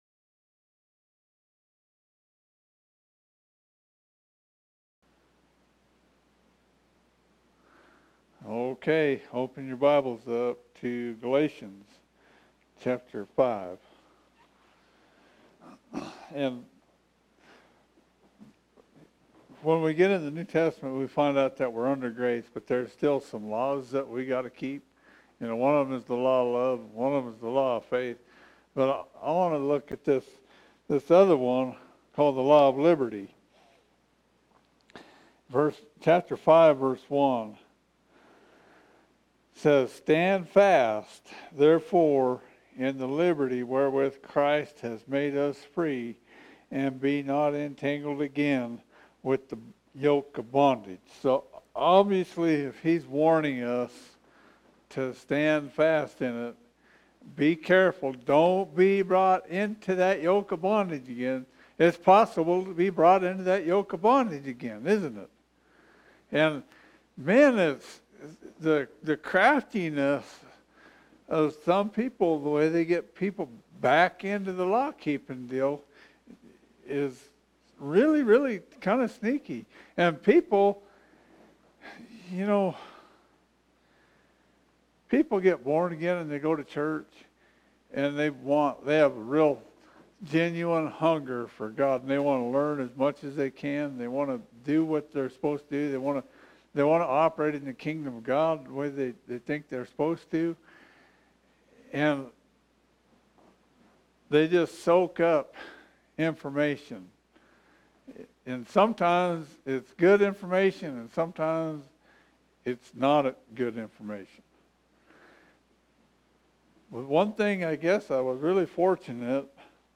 The Church In The Mountains is a non-denominational Bible oriented church in Fort Davis Texas.
Wednesday Bible Study